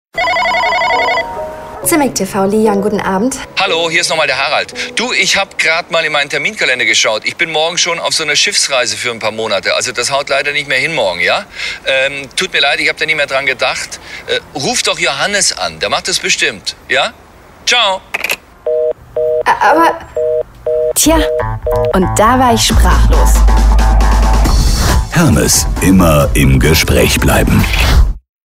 Harald Schmidt für Hermes – Imagevideo